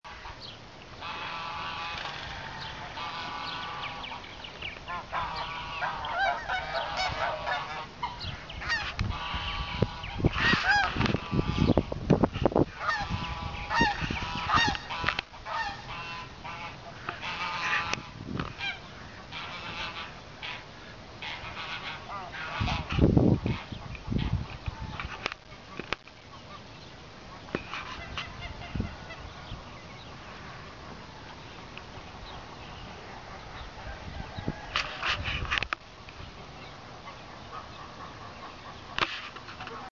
Lots Of Geese Bouton sonore